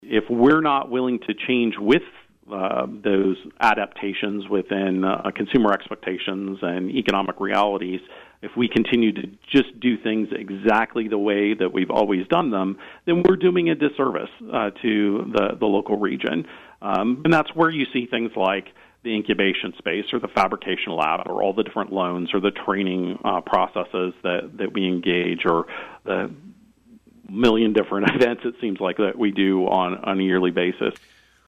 an interview on KVOE’s Talk of Emporia Monday morning